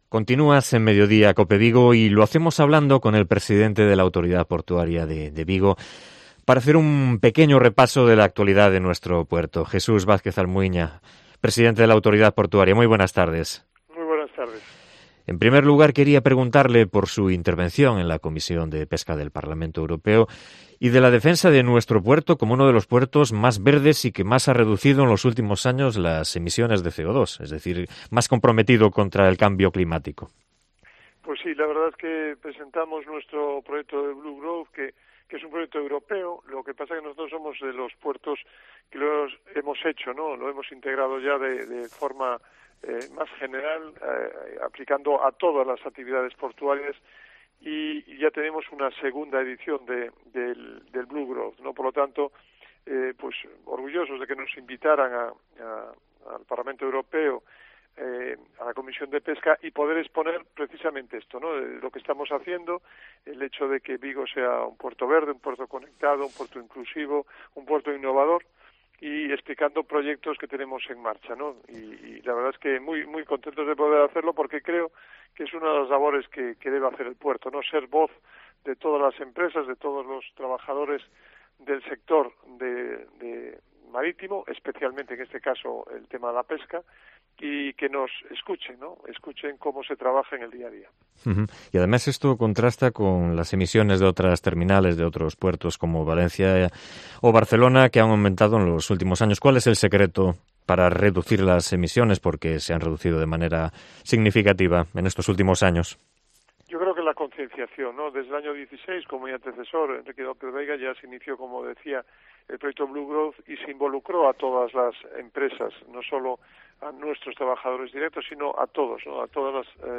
Entrevista a Jesús Vázquez Almuiña, presidente de la Autoridad Portuaria de Vigo